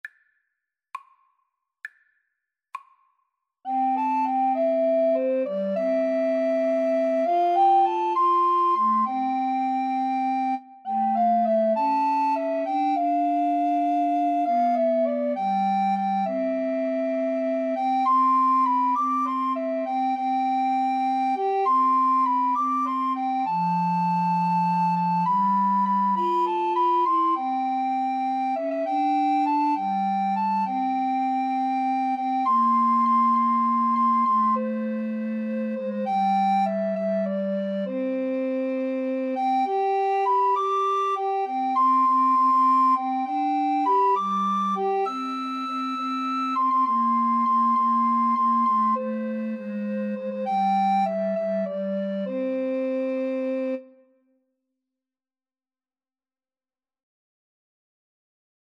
Alto RecorderTenor RecorderBass Recorder
C major (Sounding Pitch) (View more C major Music for Recorder Trio )
6/8 (View more 6/8 Music)